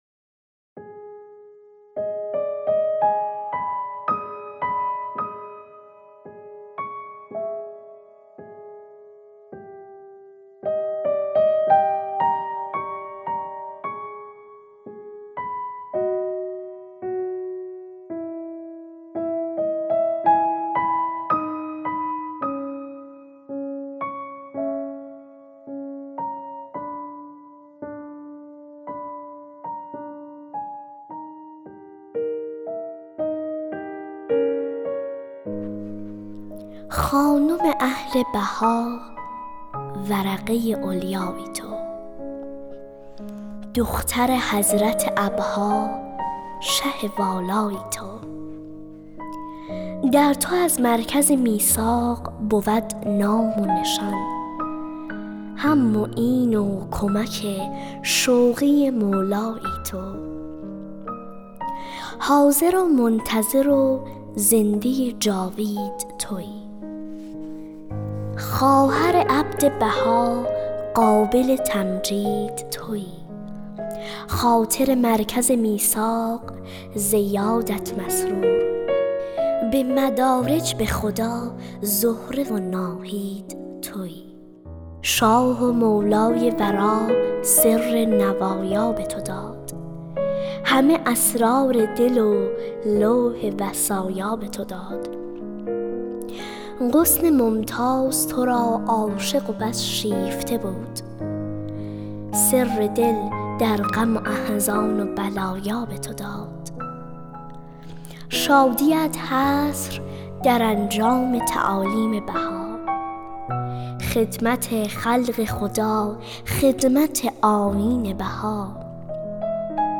دکلمه اشعار همراه با موسیقی